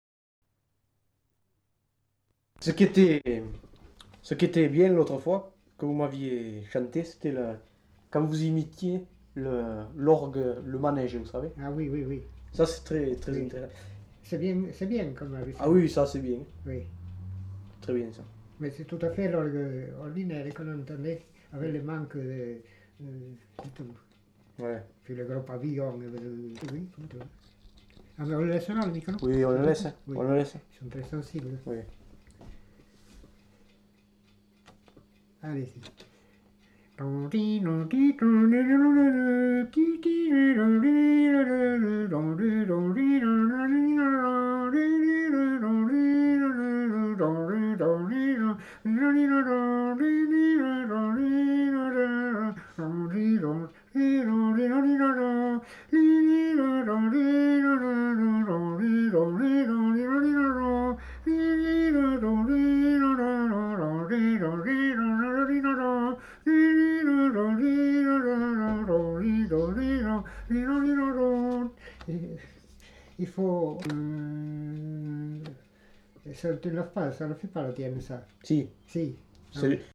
Imitation d'instruments de musique et de cris d'animaux
enquêtes sonores